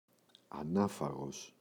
ανάφαγος [a’nafaγos]